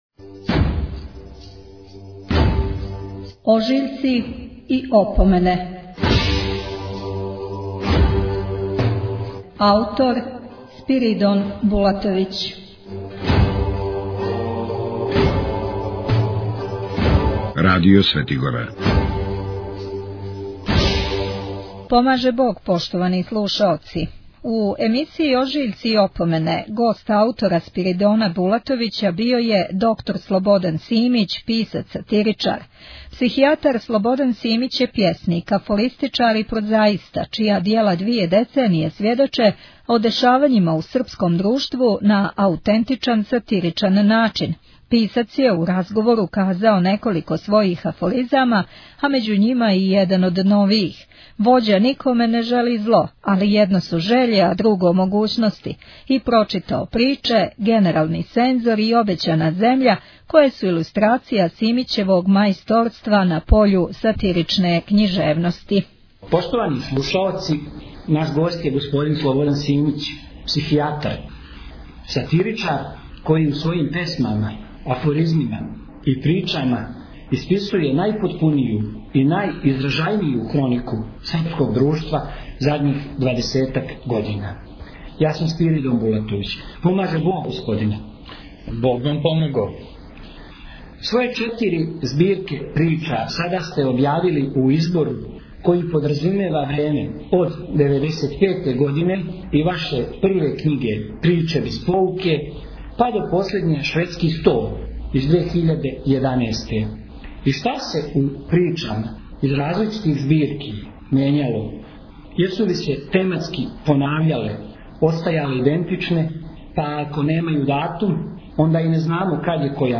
Писац је у нашем разговору казао неколико својих афоризама а међу њима и један од новијих: “Вођа никоме не жели зло.